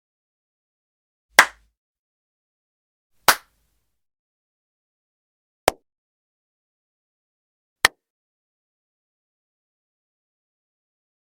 Free SFX sound effect: Hand Clap Single Shot Variations.
Hand Clap Single Shot Variations
yt_RyzTC69Uzrk_hand_clap_single_shot_variations.mp3